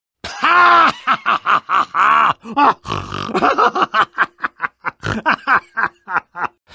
Risada aporcalhada e anasalada do Spy de Team Fortress 2.
risada-spy-team-fortress.mp3